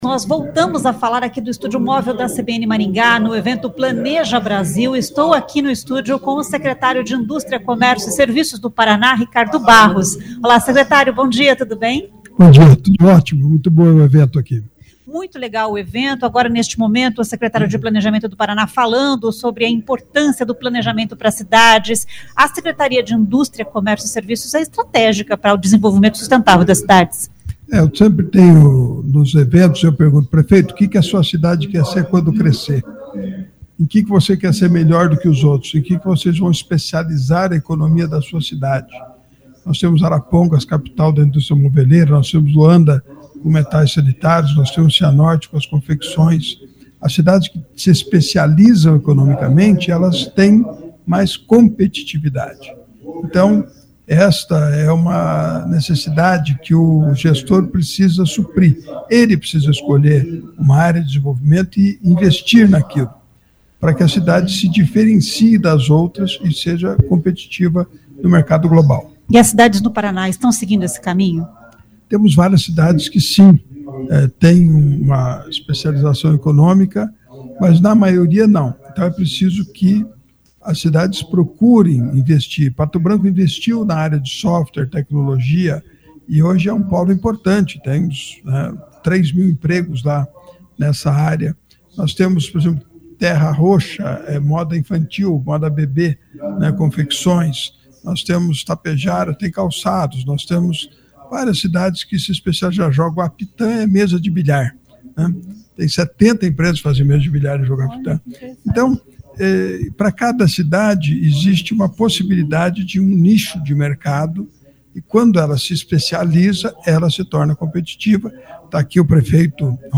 A entrevista foi realizada no estúdio móvel CBN instalado no local do evento.